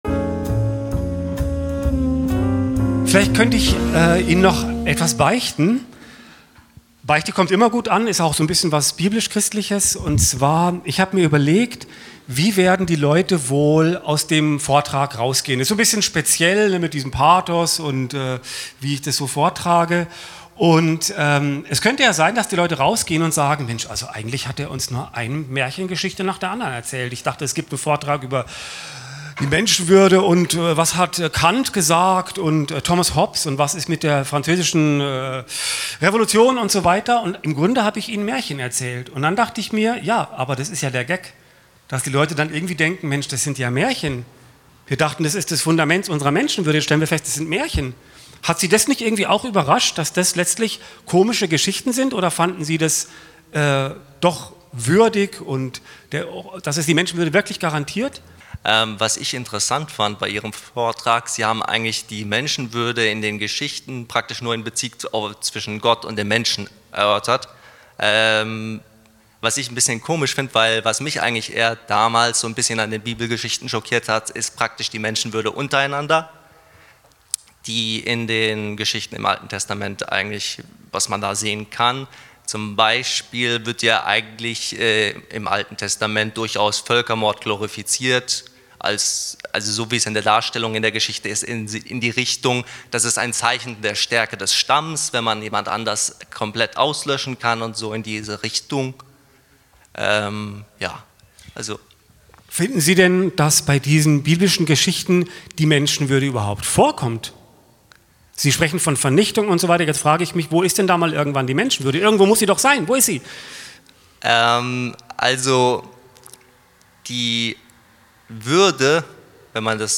Inhalt von Teil 5: Der letzte Teil enthält die besten Szenen aus der Diskussion mit dem Publikum .